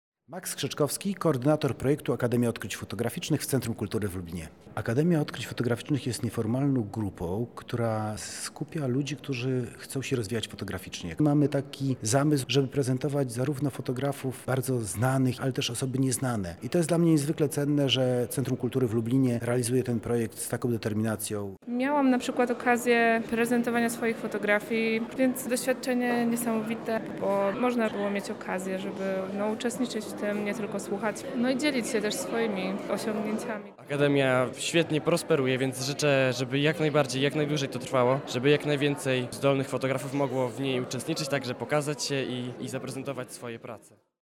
Jeden z naszych reporterów miał okazję uczestniczyć w ich otwarciu, porozmawiać z autorem oraz wysłuchać historii ludzi związanych z Akademią: